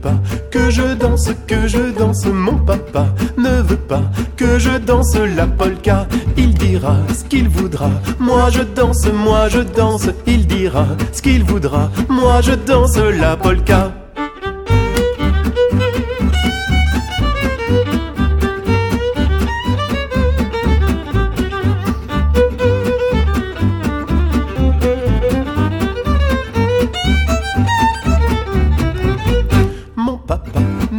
cinq musiciens de jazz manouche.